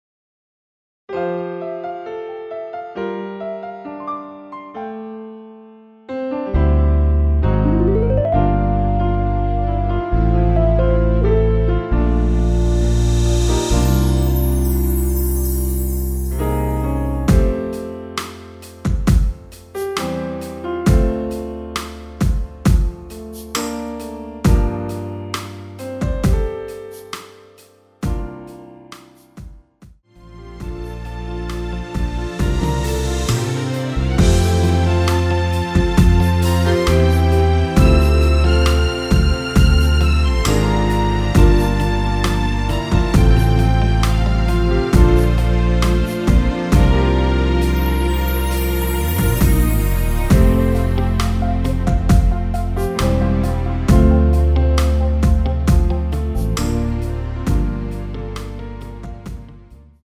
엔딩이 페이드 아웃이라 엔딩을 만들어 놓았습니다.
앞부분30초, 뒷부분30초씩 편집해서 올려 드리고 있습니다.